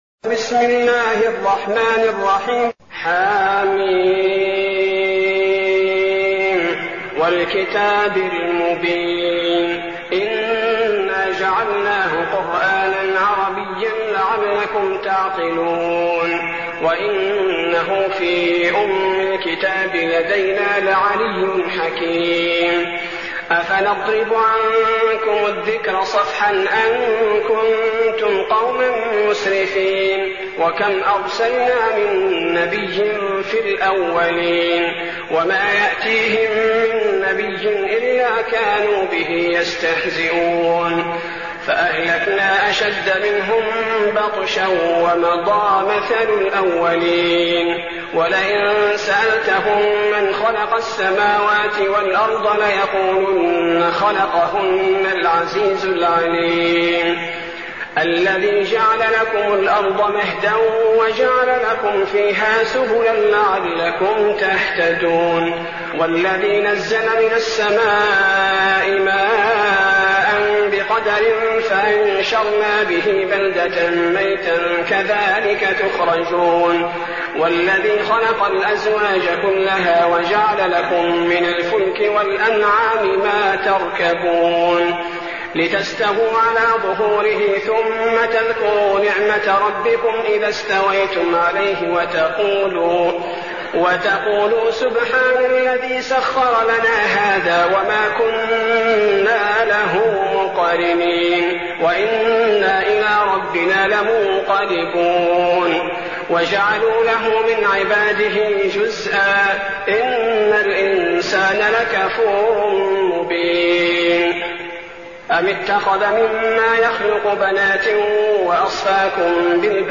المكان: المسجد النبوي الشيخ: فضيلة الشيخ عبدالباري الثبيتي فضيلة الشيخ عبدالباري الثبيتي الزخرف